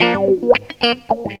GTR 84 GM.wav